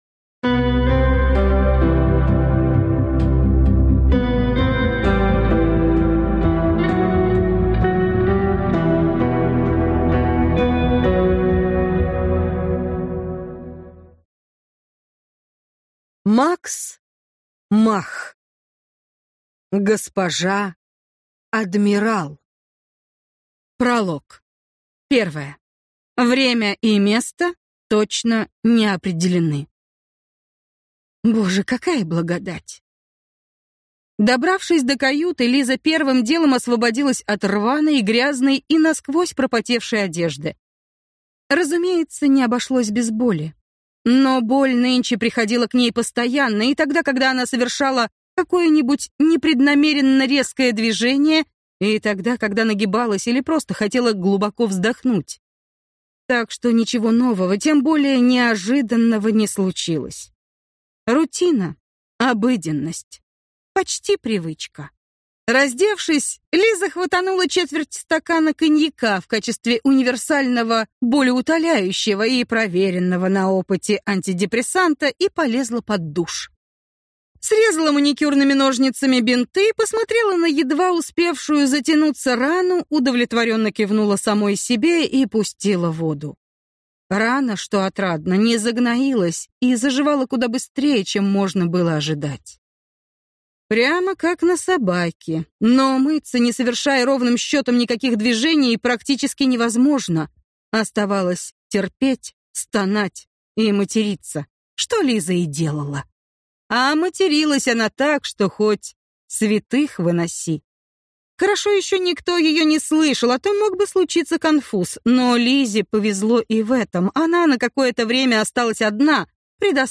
Аудиокнига Госпожа адмирал | Библиотека аудиокниг